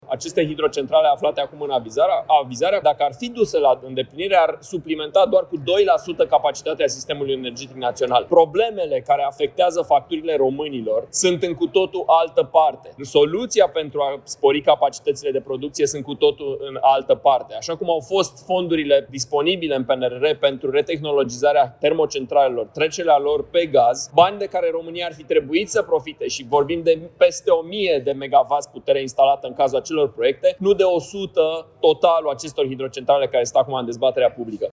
Deputatul USR Allen Coliban, fost primar al Brașovului: „Soluția pentru a spori capacitățile de producție sunt cu totul în altă parte”